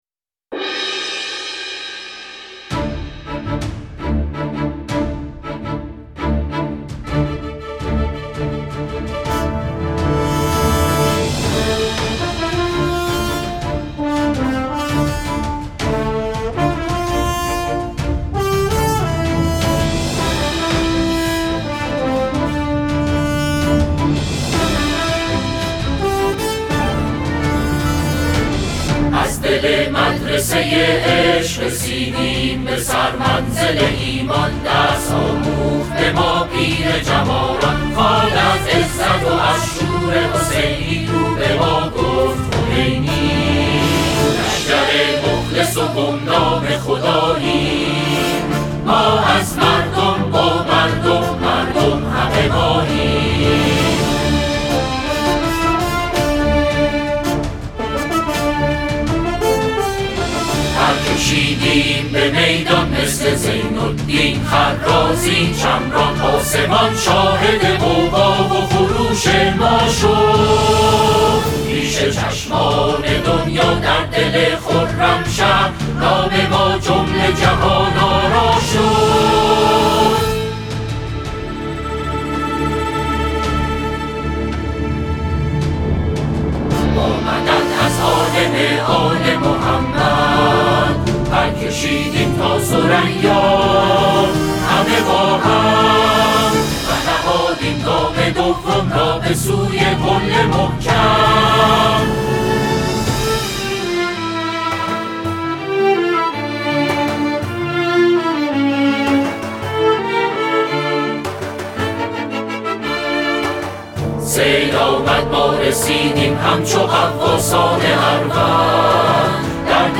ملودی‌ای قوی و انگیزشی